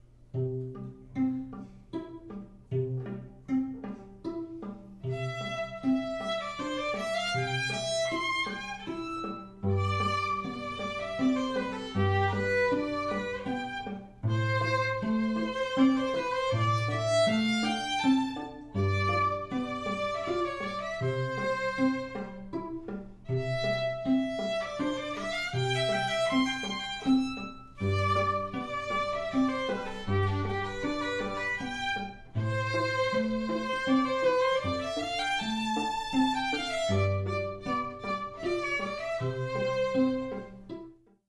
L’arlesienne Menuet – Simply Strings Quartet
Larlesienne-Menuet-quartet.mp3